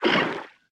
Sfx_creature_babypenguin_hold_equip_under_01.ogg